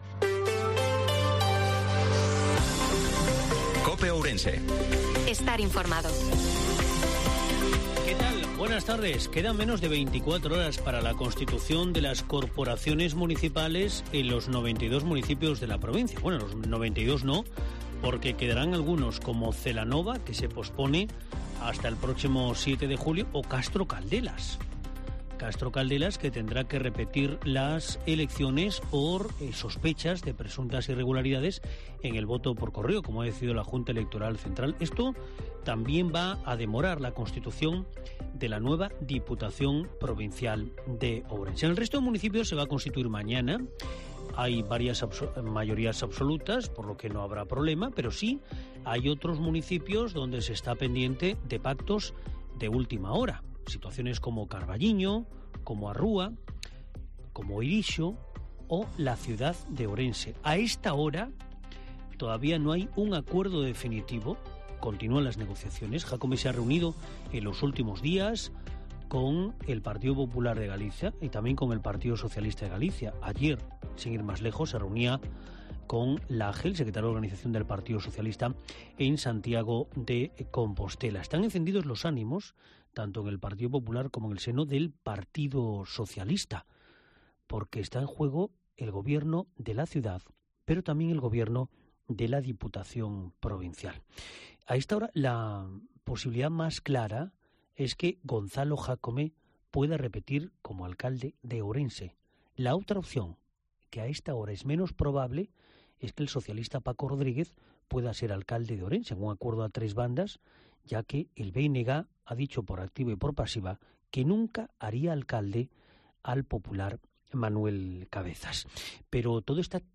INFORMATIVO MEDIODIA COPE OURENSE16/06/2023